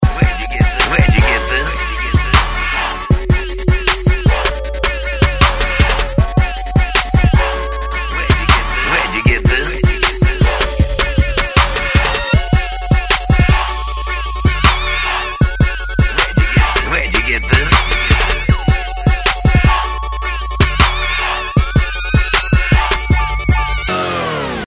1 Beat made up of 10+loops, for you the producer to edit.
***AFTER PURCHASE THE VOICE TAG IS REMOVED***